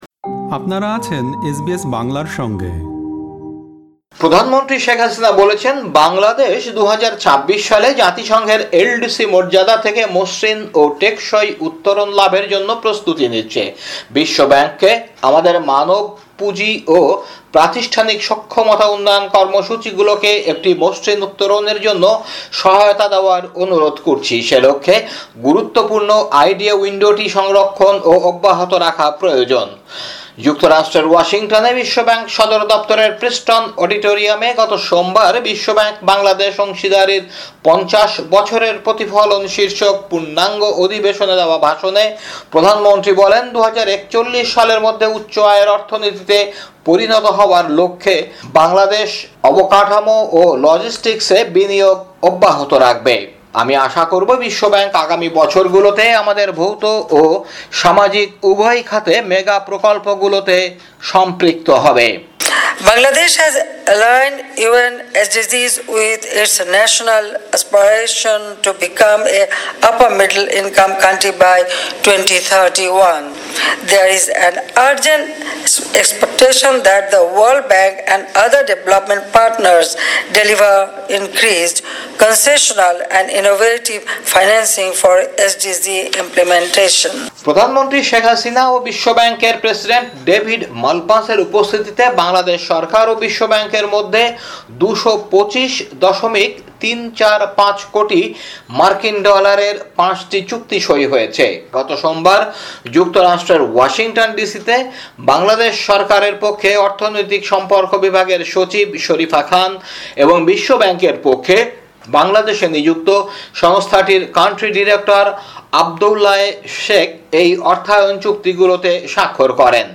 বাংলাদেশের সাম্প্রতিক খবর, ৬ মে, ২০২৩